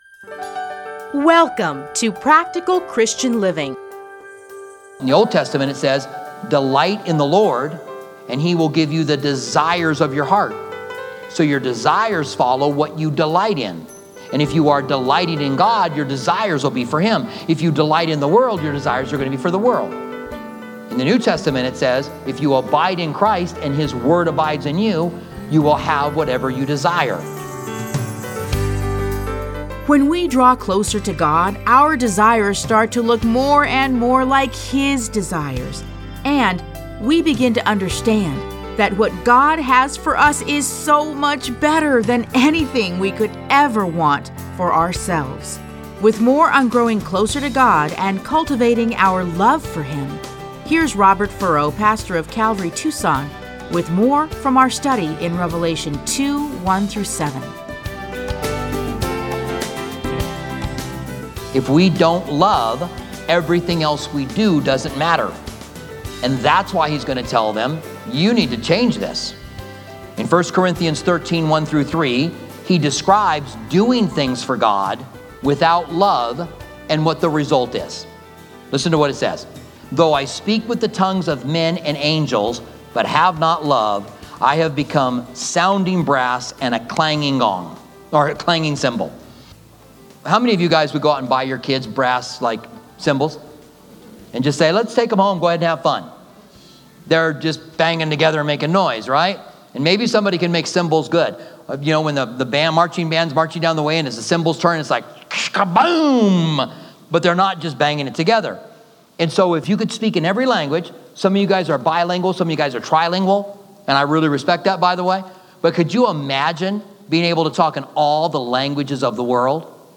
Listen to a teaching from Revelation 2:1-7.